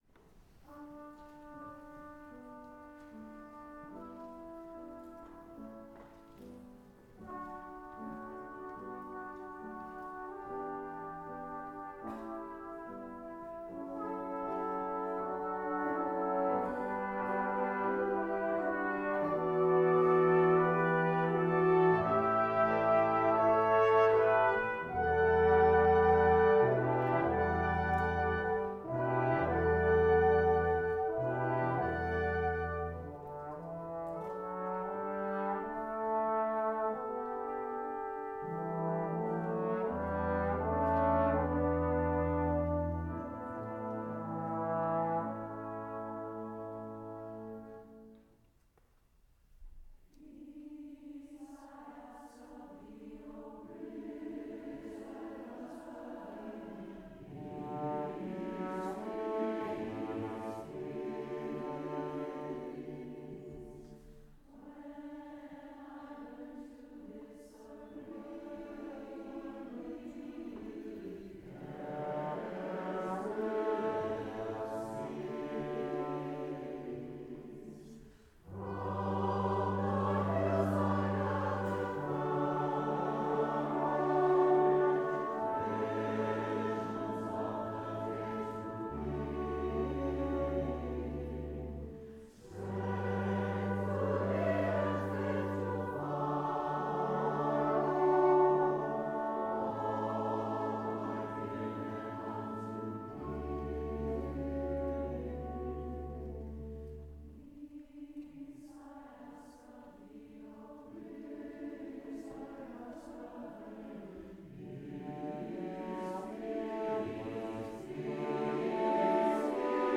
for SATB Chorus, Brass Quintet, Percussion, and Piano (2005)
This is a gentle song.